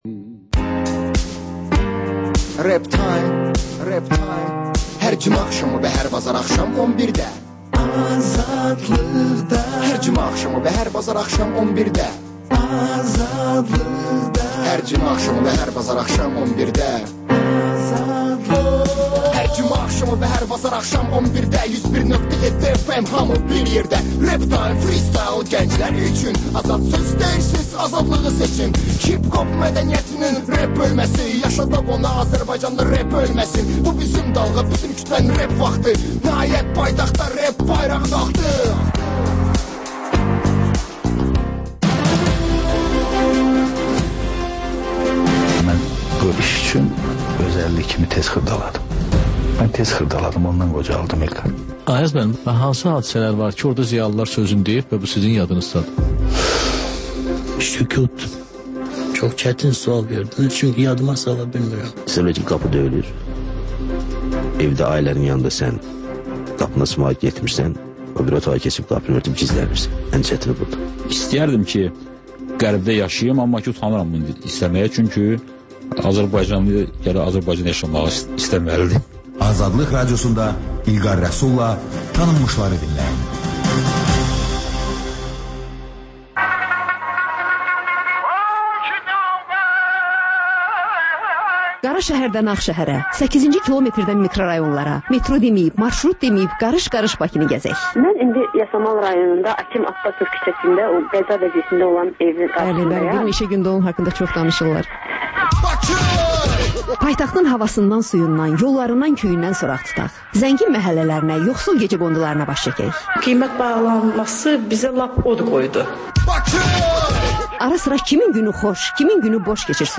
Ölkənin tanınmış simaları ilə söhbət